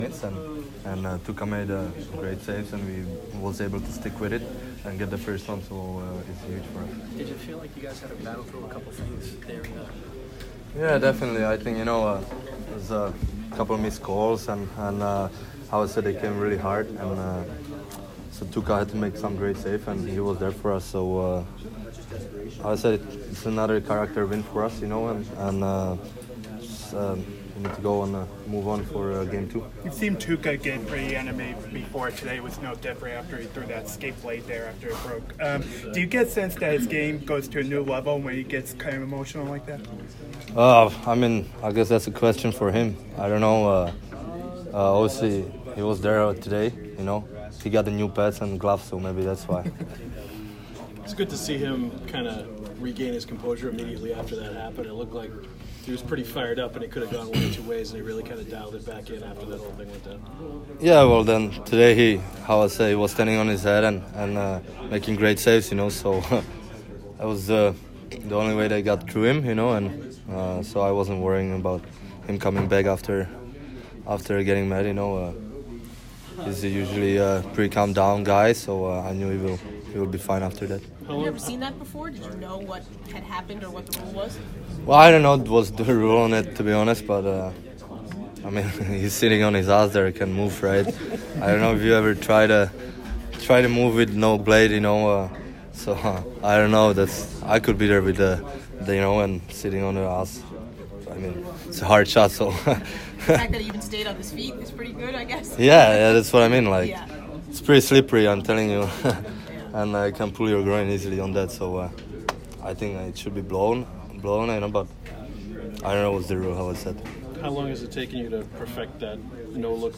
Patrice Bergeron post-game 4/28